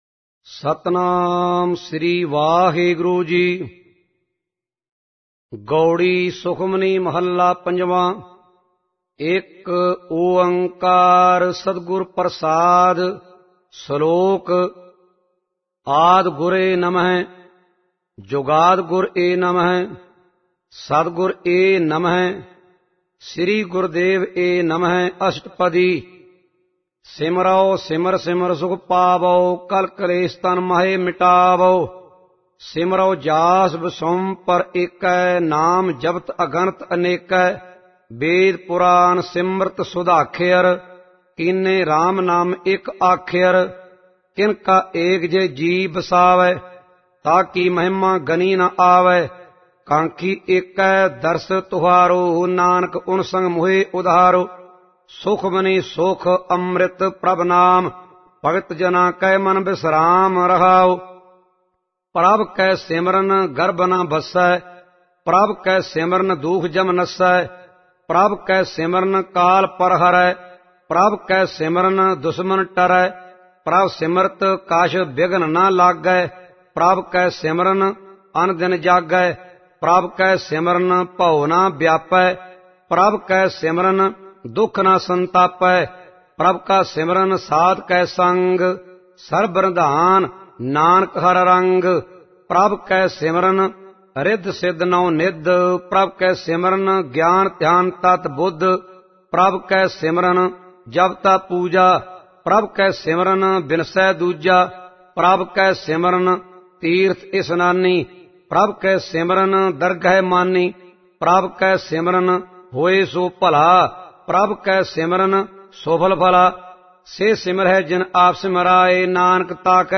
Gurbani Ucharan